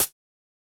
Perc (5).wav